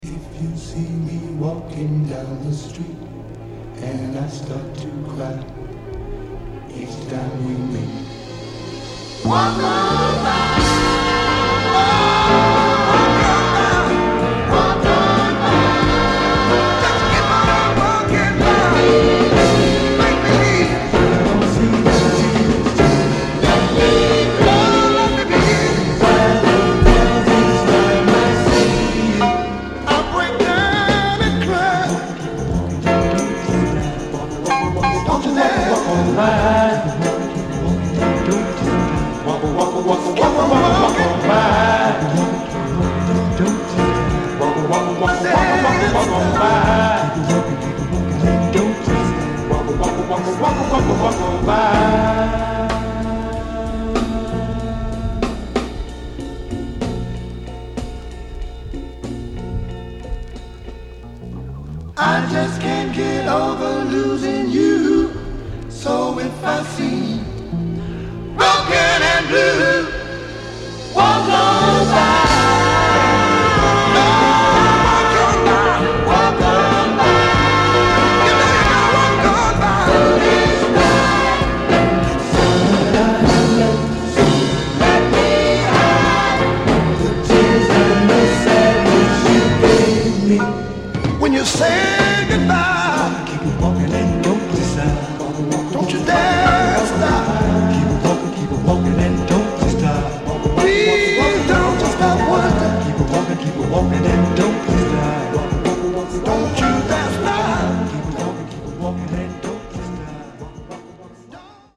anthemic